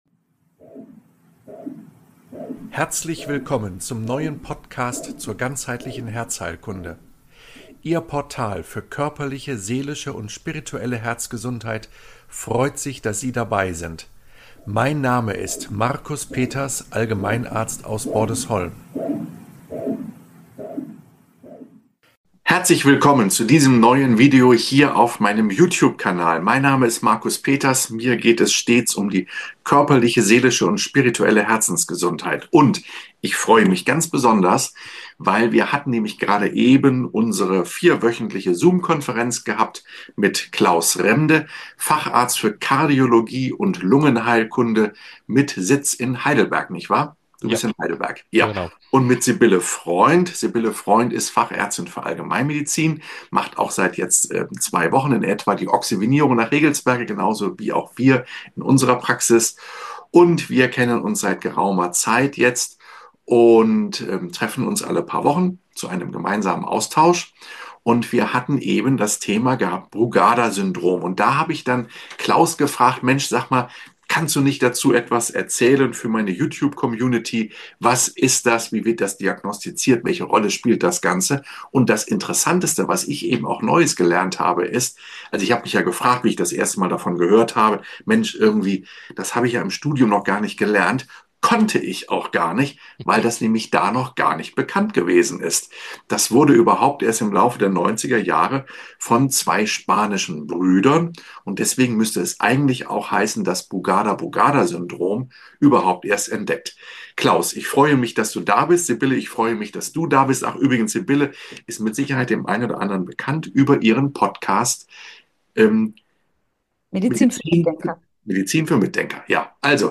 Das Brugada-Syndrom - Ein Gespräch